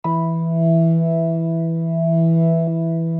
B3LESLIE E 4.wav